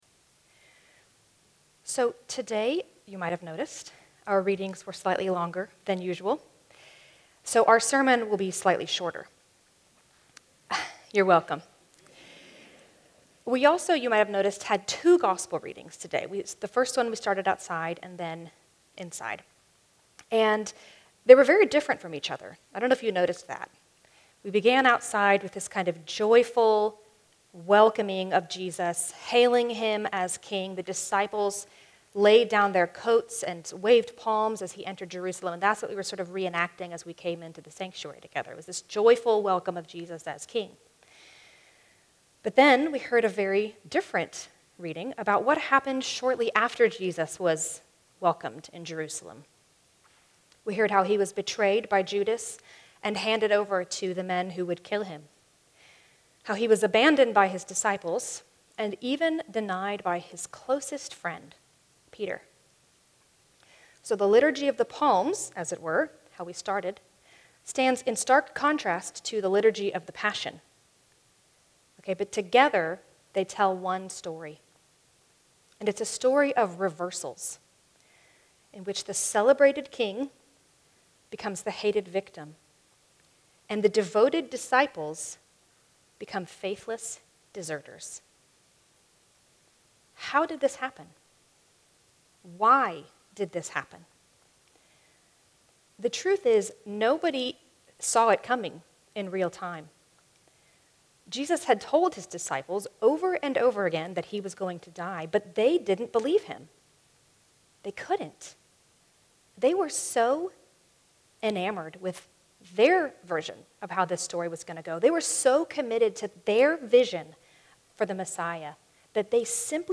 (Though the song of response is not included, you can listen to it by searching for "Go to Dark Gethsemane" in your preferred music app.)